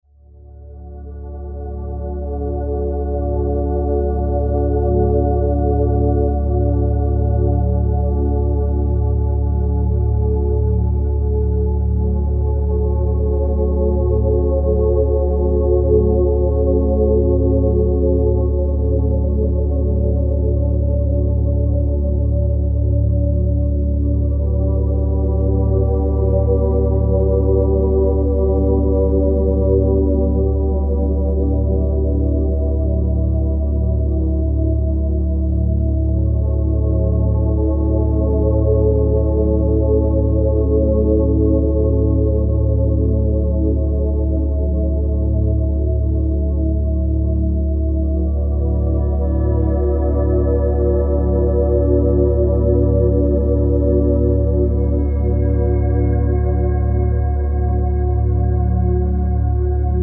Music for concentration.